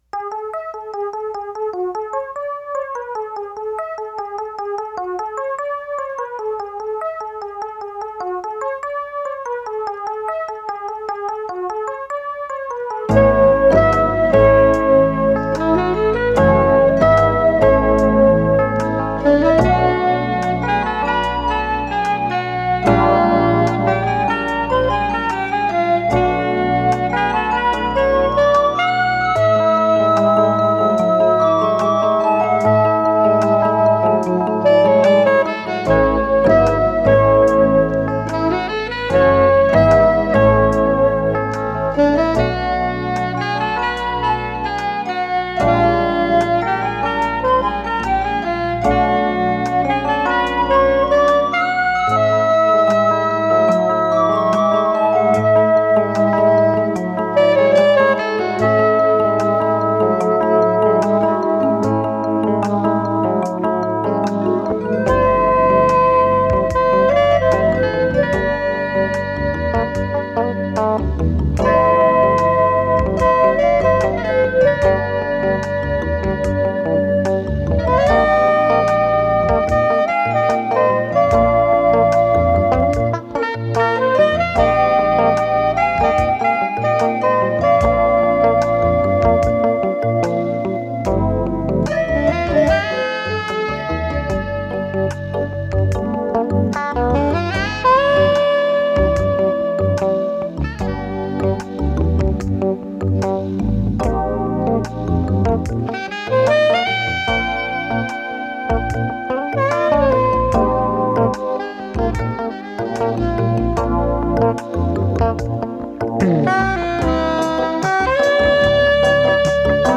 lead synthesizer, synthesizer solos.
synthesizer accompaniment, synth-bass and drum sequencing.
saxophone.
electric guitar.